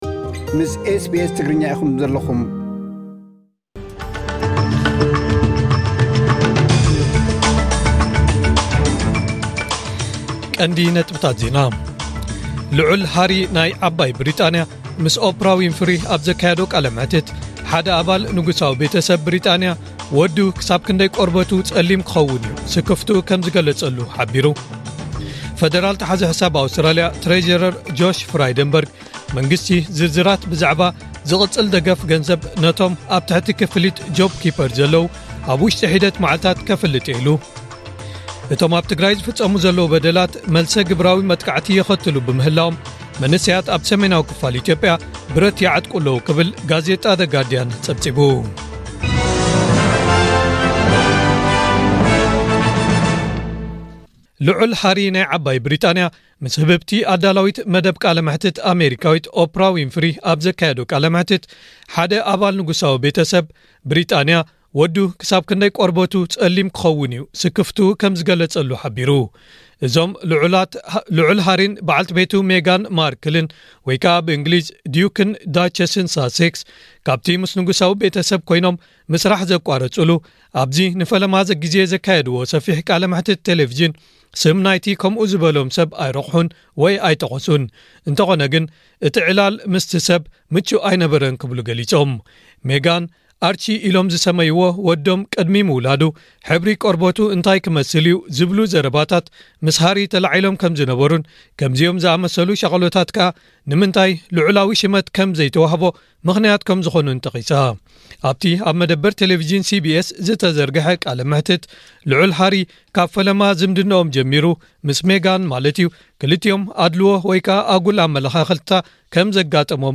ዕለታዊ ዜና ኤስቢኤስ ትግርኛ (08/03/2021)